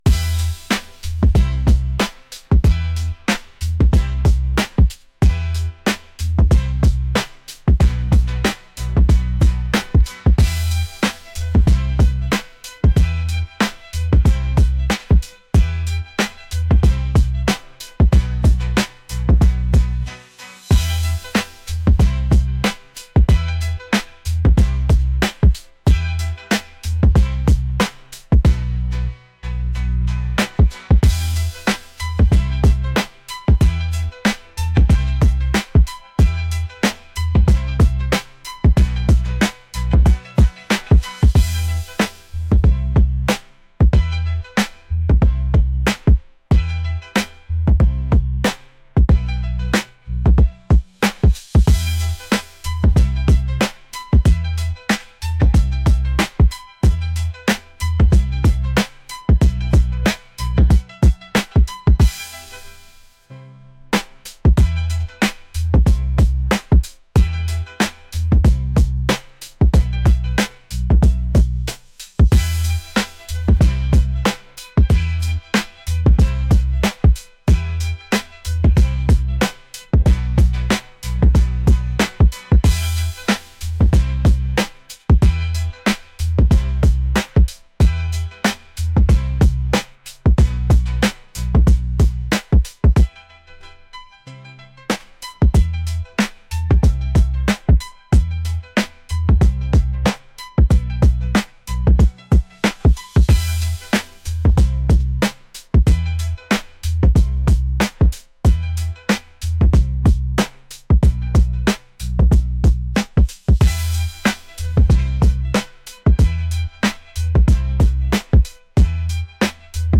energetic | upbeat